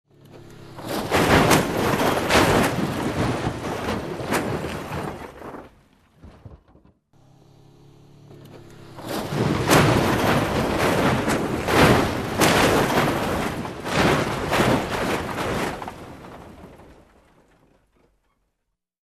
Звуки столкновения
1. Звук опрокидывания машины в ДТП n2. Шум переката авто при аварии